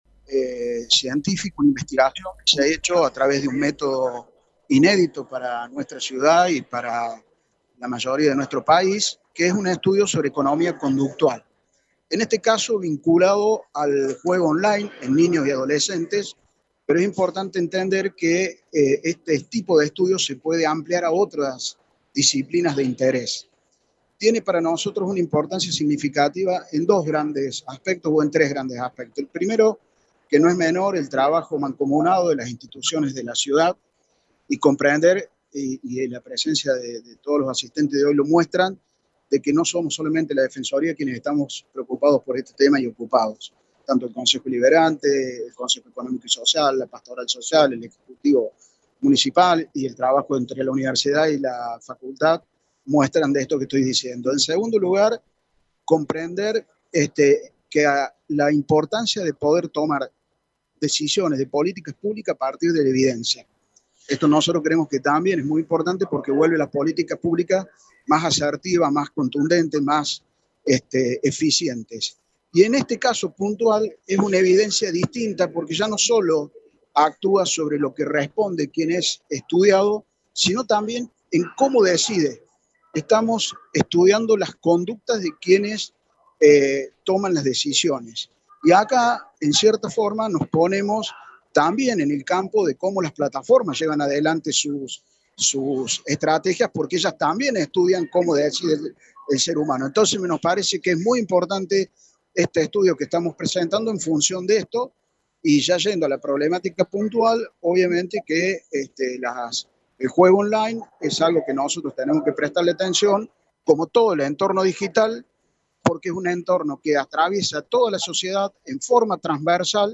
Por su parte, el Defensor del Pueblo, Daniel Frangie, señaló que este trabajo realizado desde la economía experimental y conductual puede ampliarse a otras disciplinas para contar con información para políticas públicas.